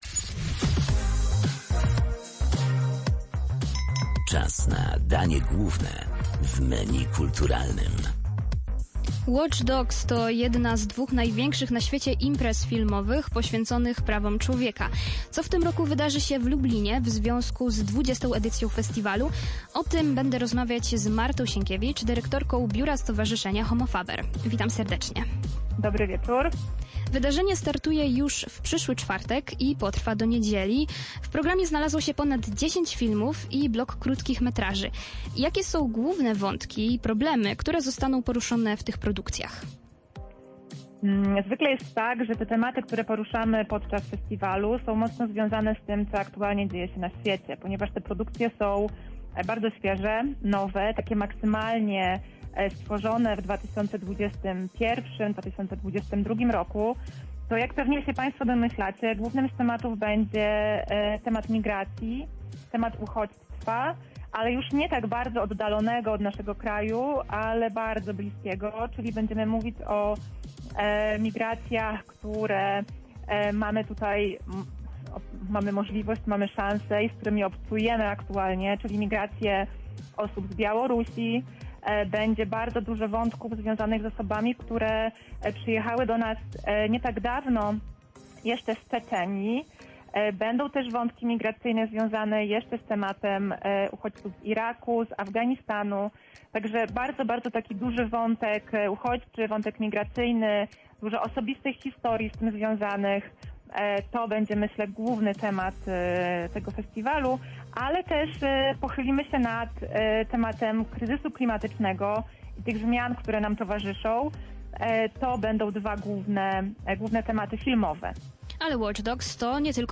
Co w tym roku wydarzy się w Lublinie w związku z nadchodzącym wydarzeniem? O tym rozmawiam